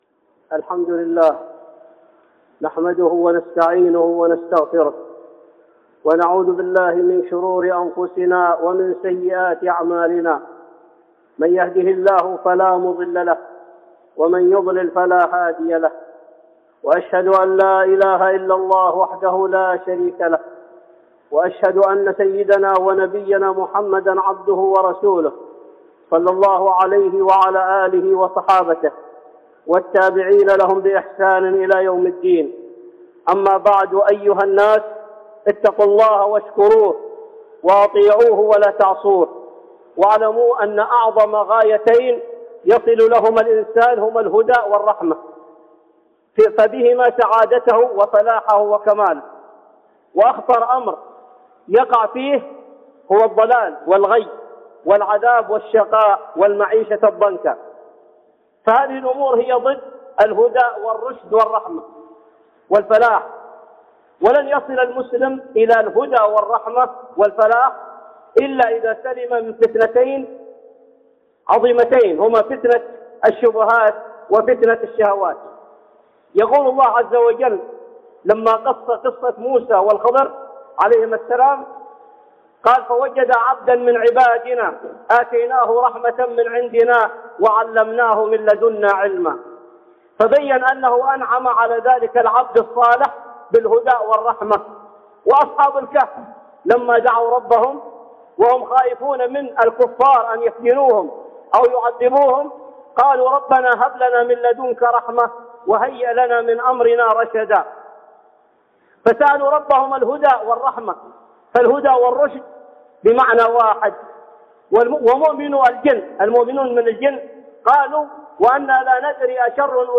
(خطبة جمعة) الهدي والرحمة لمن سلم من الشبهات